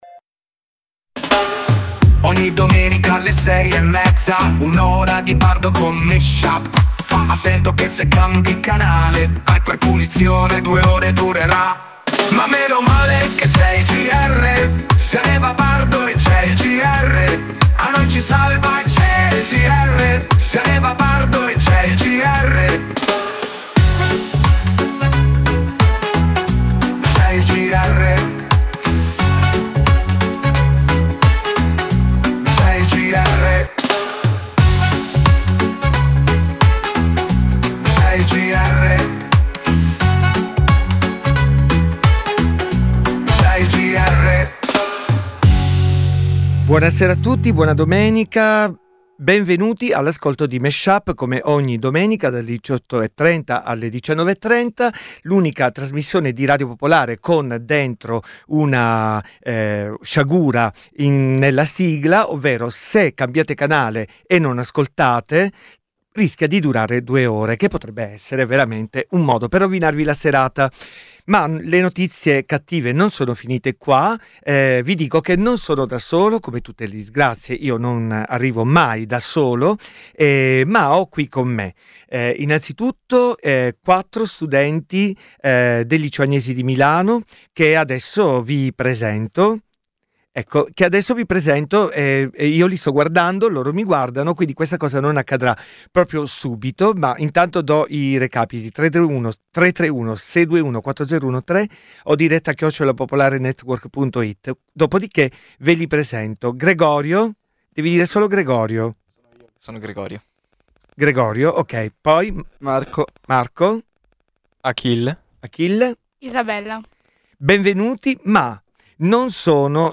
Musica che si piglia perché non si somiglia.
Ogni settimana un dj set tematico di musica e parole